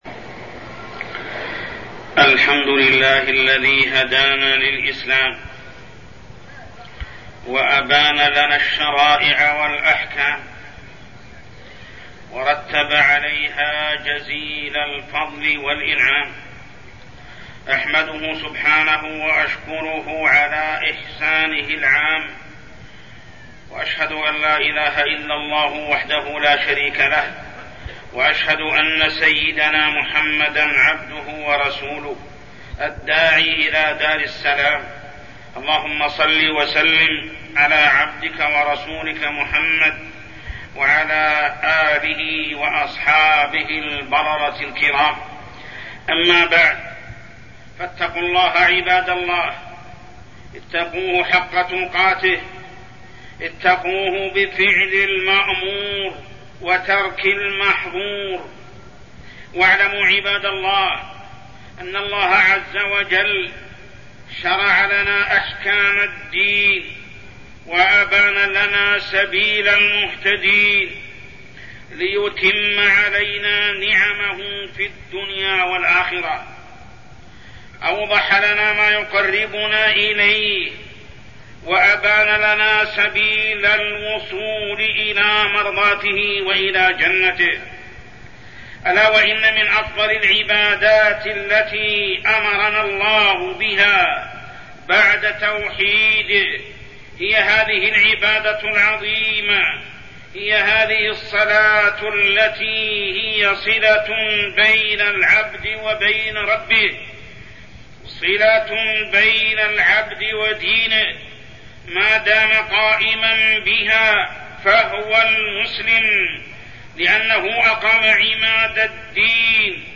تاريخ النشر ١٢ جمادى الأولى ١٤٠٨ هـ المكان: المسجد الحرام الشيخ: محمد بن عبد الله السبيل محمد بن عبد الله السبيل الصلاة